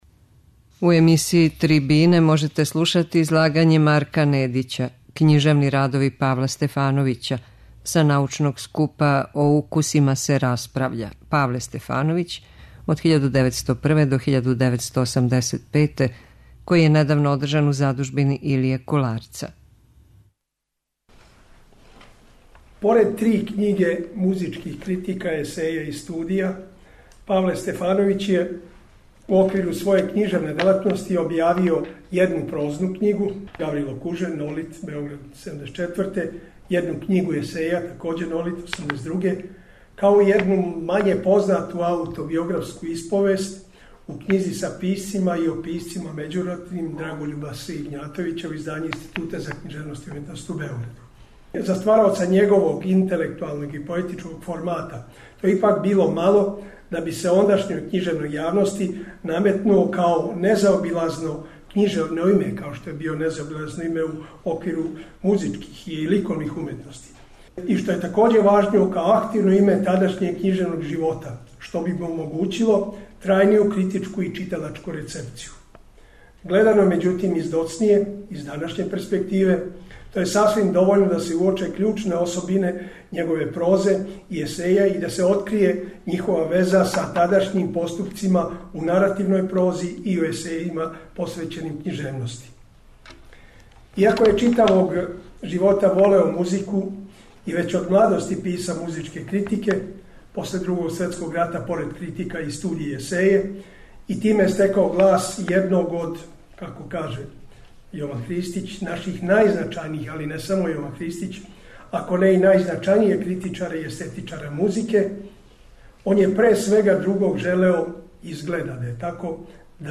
Мултидисциплинарни научни скуп